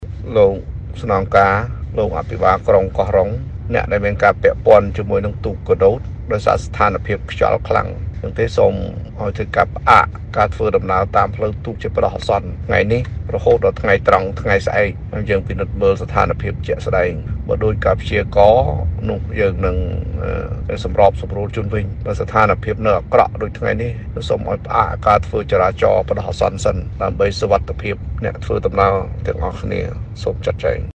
សំឡេង លោកគួច ចំរើន៖